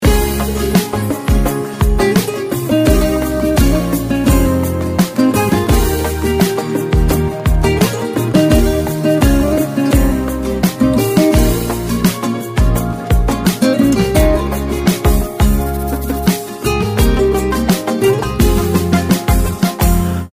(بدون کلام)